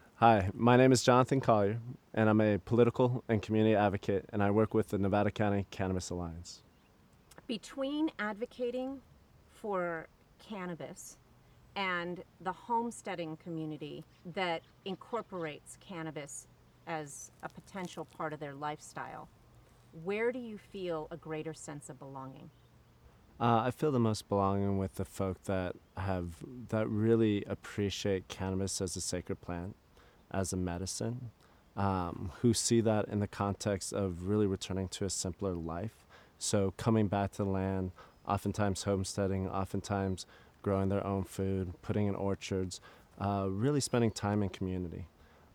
A sample of the interview